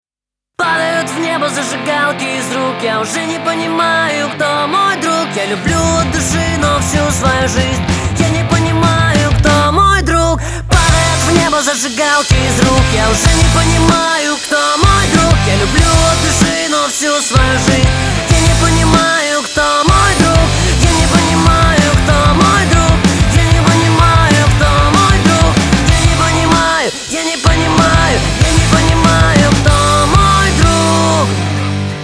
• Качество: 320, Stereo
мужской вокал
громкие
заводные
русский рок